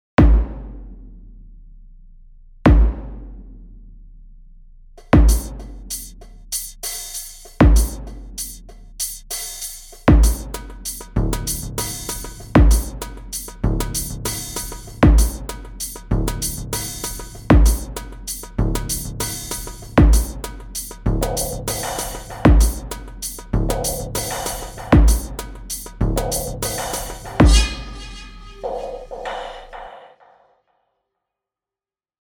Ebenfalls für Filmmusik gedacht: „Framo“ bei 97 BPM:
Hier taucht plötzlich ein Bass auf – in Wirklichkeit ein Frame-Drum Sample mit hohem tonalem Anteil.